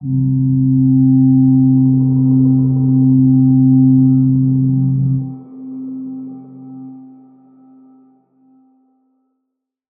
G_Crystal-C4-mf.wav